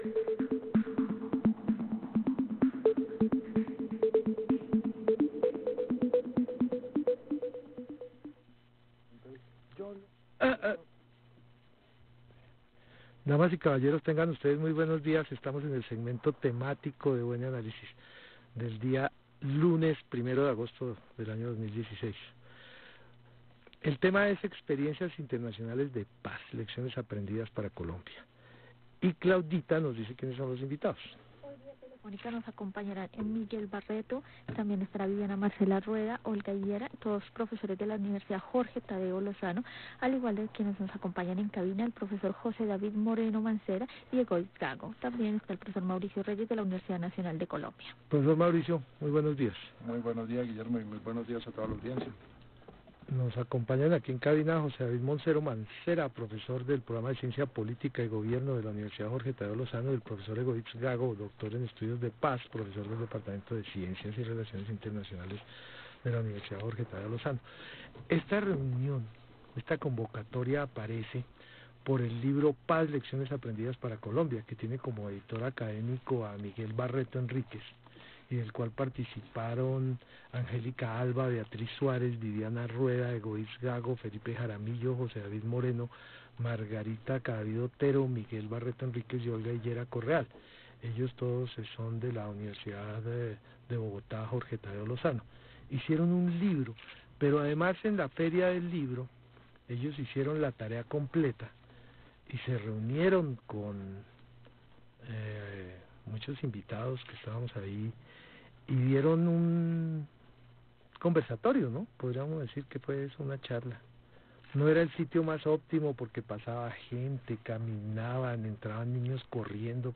Los aprendizajes recogidos en el libro presentado en la pasada Feria del Libro de Bogotá y sus autores fueron protagonistas del segmento temático del programa radial de la Universidad Nacional.